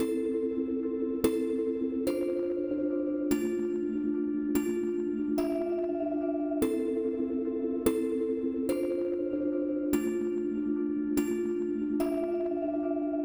Billion Benz_Piano.wav